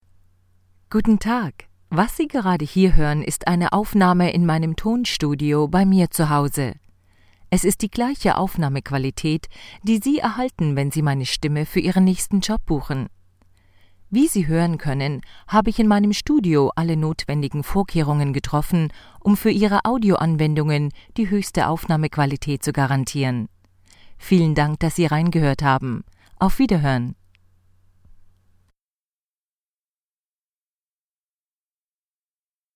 Almanca Seslendirme
Kadın Ses